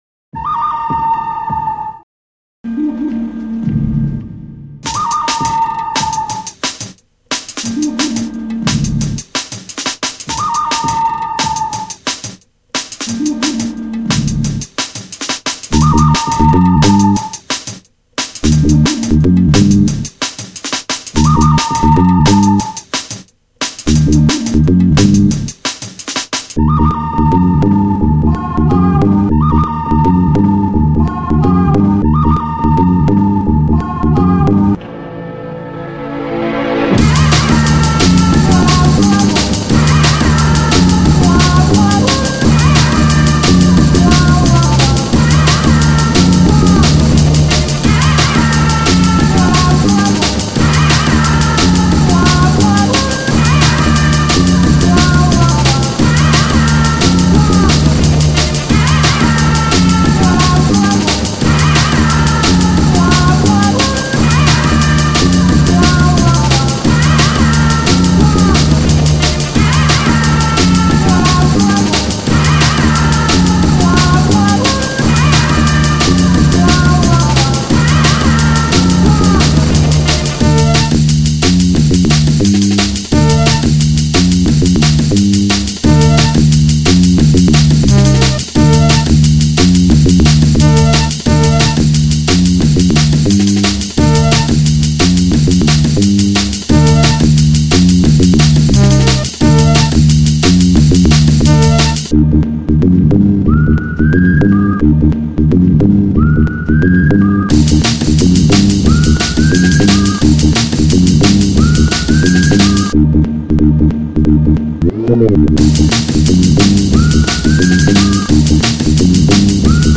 Ремикс на саундтрек к одноименному фильму d'n'b 4.22 Mb